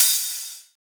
Index of /neuro/Optiv & BTK/Drums - One Shots
Open Hats (4).wav